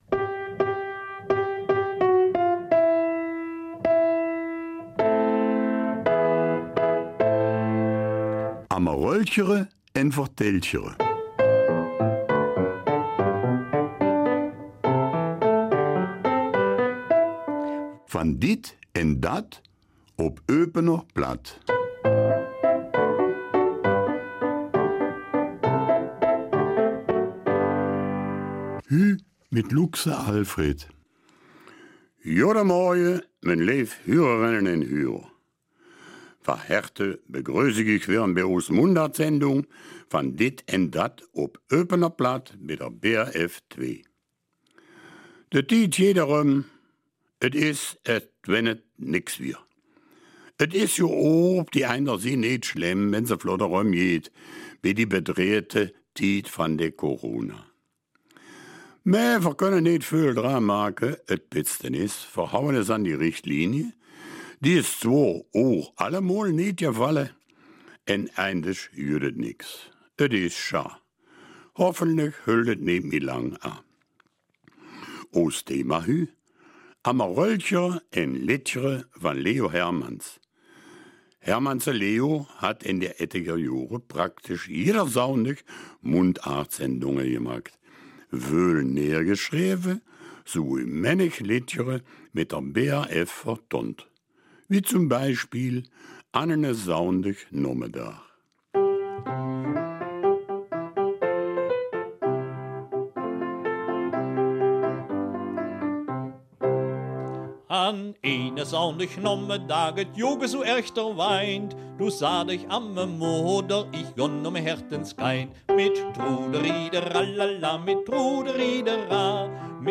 Eupener Mundart - 25. Oktober